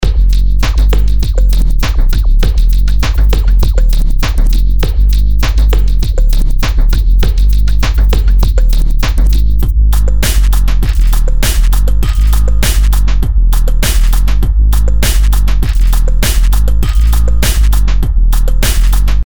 Hier habe ich die End-Frequez des Oszillatorsweeps zwischen 110 und 40 Hertz moduliert, um ein zyklisches, tonales Brummen zu erzeugen (Grundlage: Preset „Almost 3“):